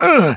Riheet-Vox_Damage_02.wav